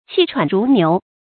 氣喘如牛 注音： ㄑㄧˋ ㄔㄨㄢˇ ㄖㄨˊ ㄋㄧㄨˊ 讀音讀法： 意思解釋： 形容大聲喘氣的模樣。